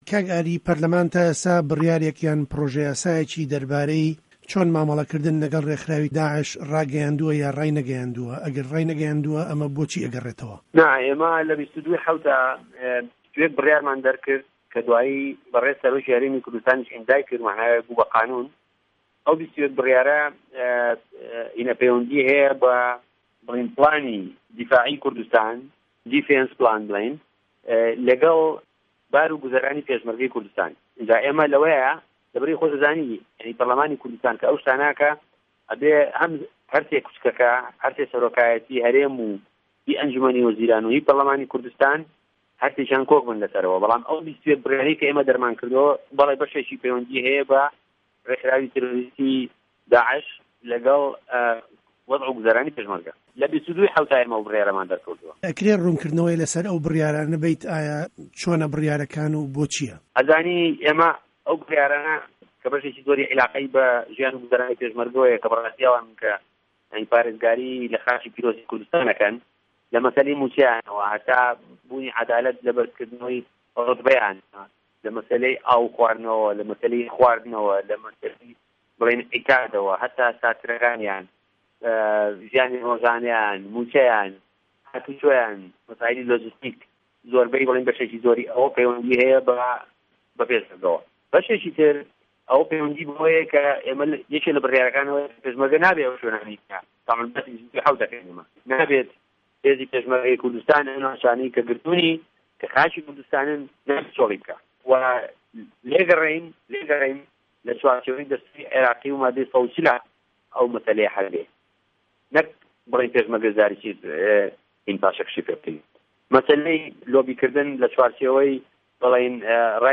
گفتوگۆ له‌گه‌ڵ ئاری هه‌رسین